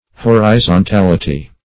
Horizontality \Hor`i*zon*tal"i*ty\, n. [Cf. F.